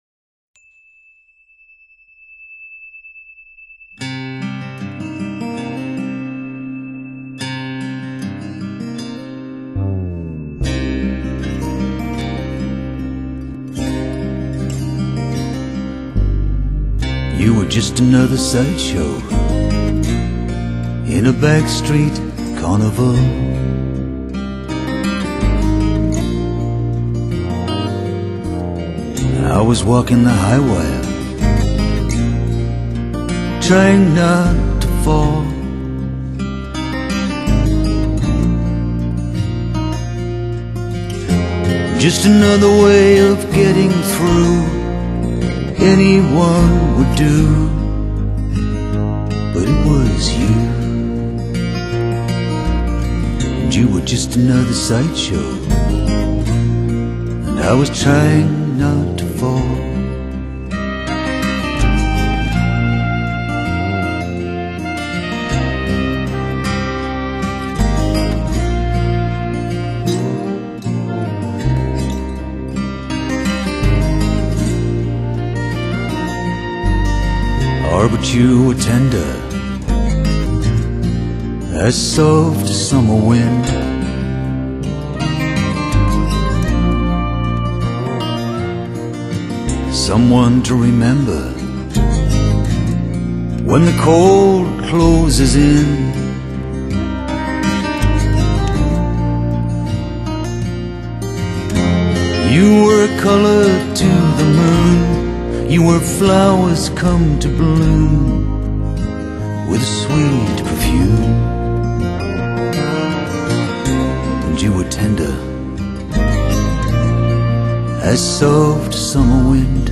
轻柔、稳重、温暖而抒情
录音精致、清澈而透明。
这张唱片的吉他录制都充满了柔软弹性与充满金属光泽的美感，于充满空间中的残影。